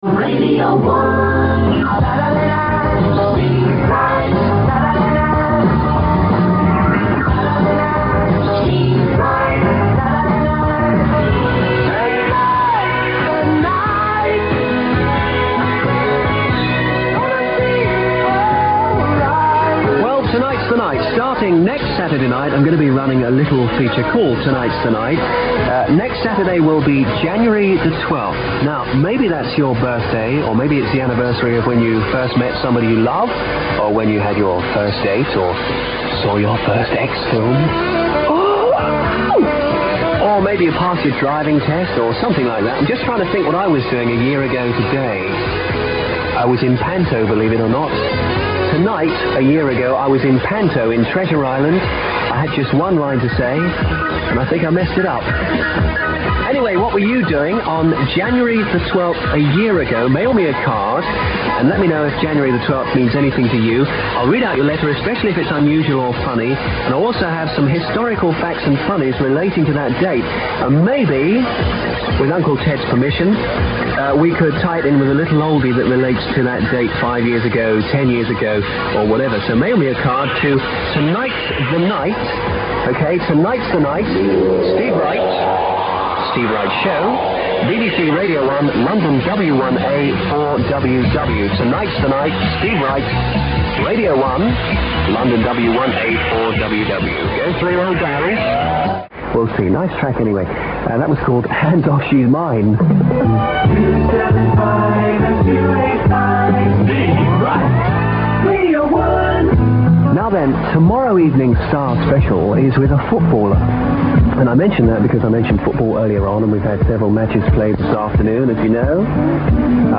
Here, enjoy Steve's first show on Radio 1 in January 1979. It was, as you'll hear, Saturday night.